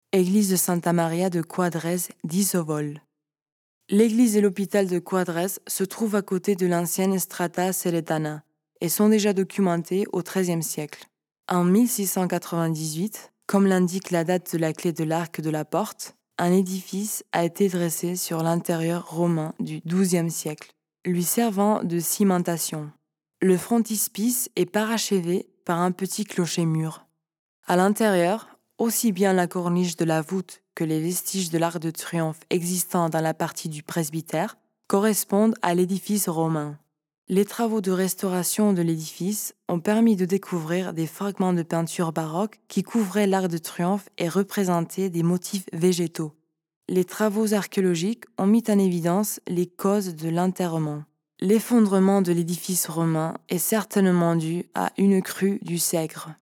Escucha la locución en la que te explicamos cómo es la iglesia de Santa Maria de Quadres.